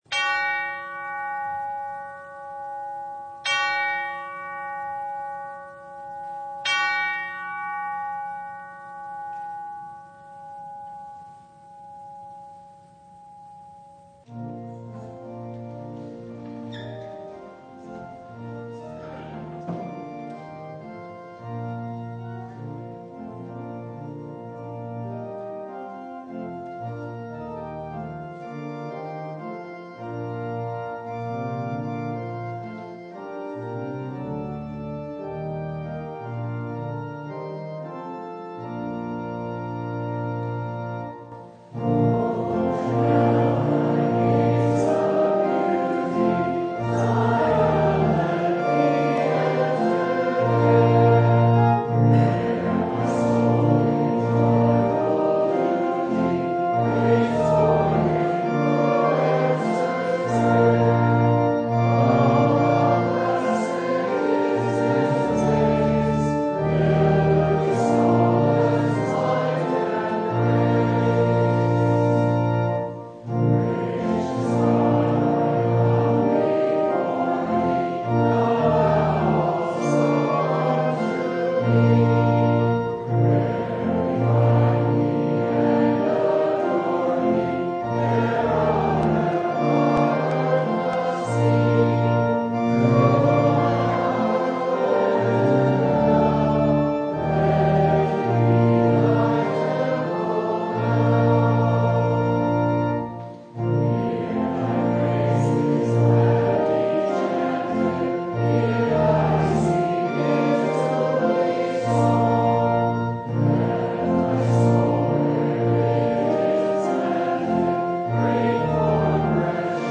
Topics: Full Service